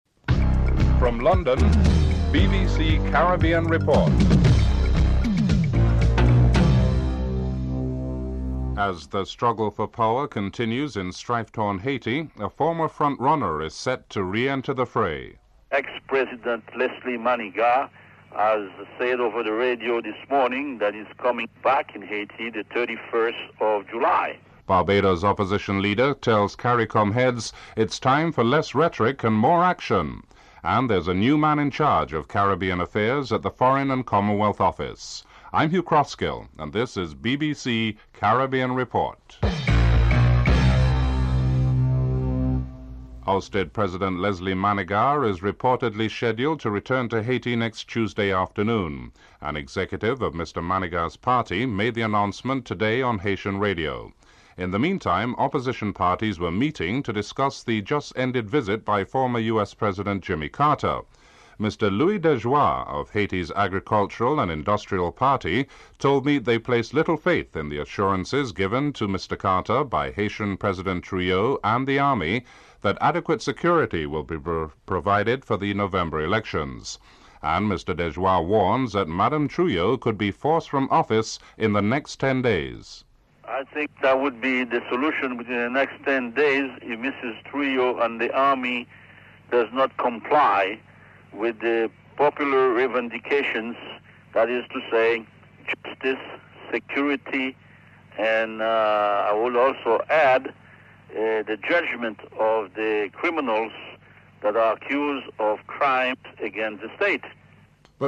Excerpt of a speech by Fidel Castro is played